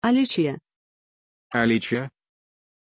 Pronunția numelui Alícia
pronunciation-alícia-ru.mp3